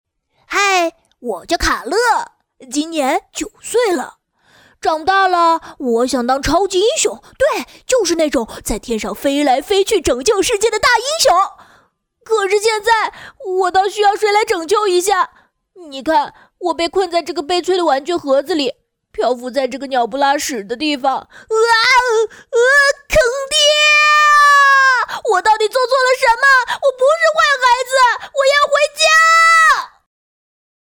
女声 游戏 英雄联盟角色模仿-13刀妹